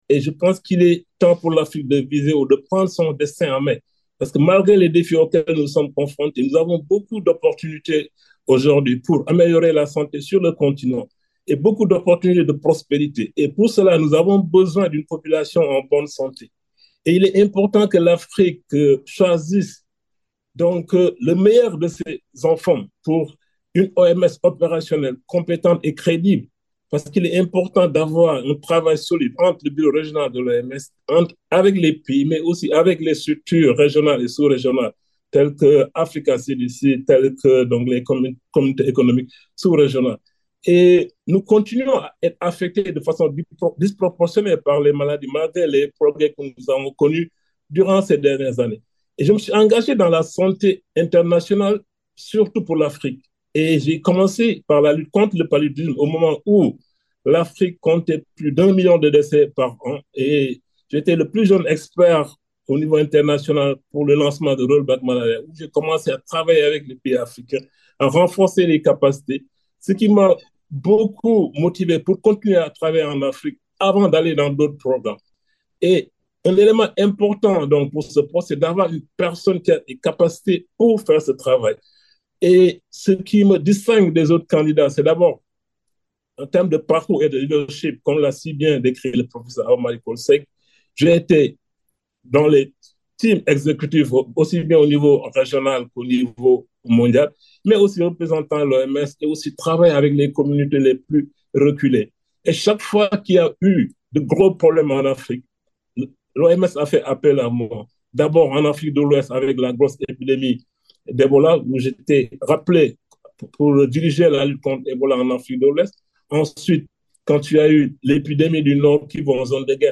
Nandritra ny webinaire natao ny 08 Aogositra 2024 no namelabelaran’ny Dr Socé Fall ireo tsara ho fantatra mikasika ny vina, ny iraka ary ireo paikady itantanany ny OMS Afrika raha toa ka izy no ho voafidy. Nambarany koa ny mampiavaka azy amin’ireo mpifaninana aminy.